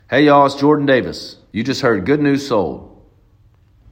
LINER Jordan Davis (Good News Sold) 3